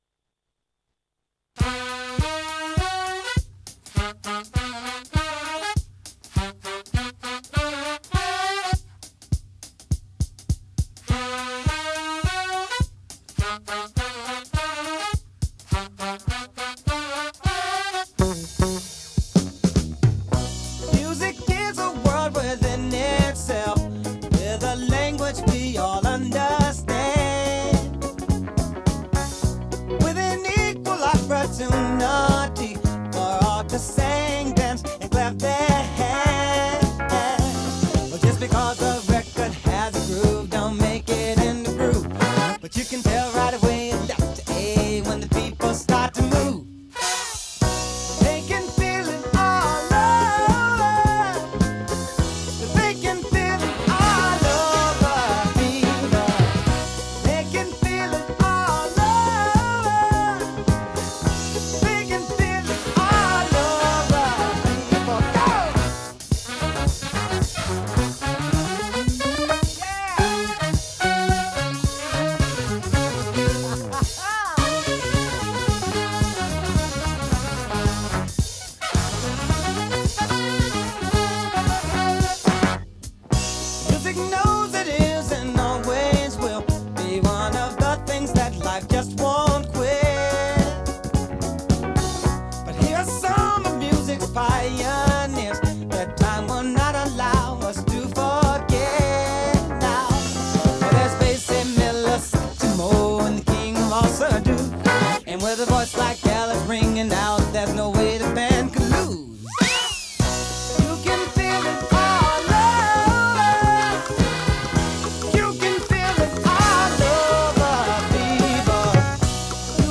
This week we're listening to some 70's Prog Soul!